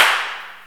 RCLAP 1.wav